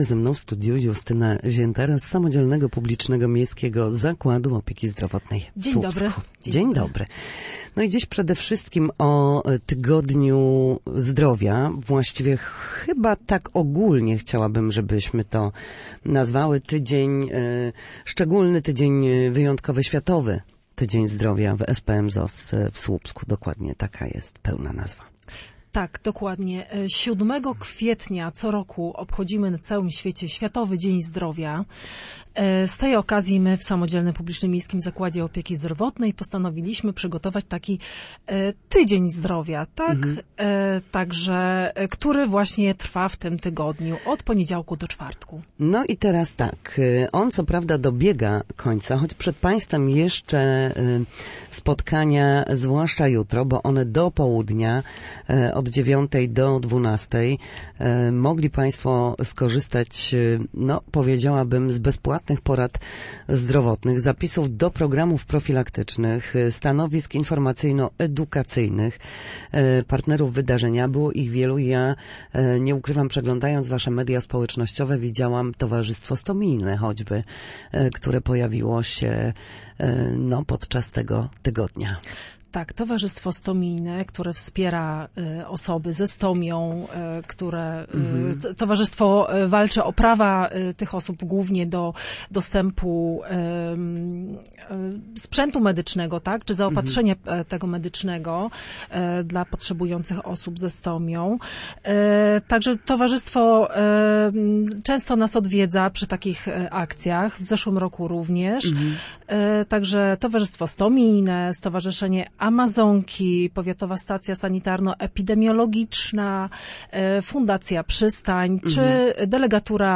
W audycji „Na zdrowie” nasi goście – lekarze i fizjoterapeuci – odpowiadają na pytania dotyczące najczęstszych dolegliwości, podpowiadają, jak leczyć się w warunkach domowych, i zachęcają do udziału w nowych projektach.